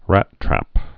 (răttrăp)